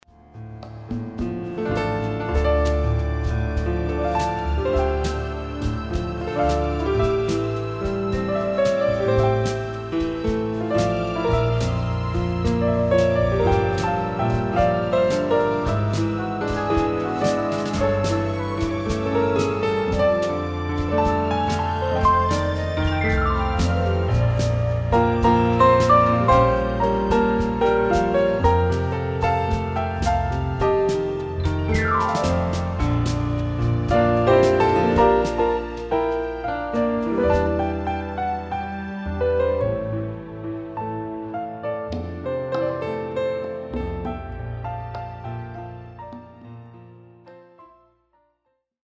Pianist
instrumental recordings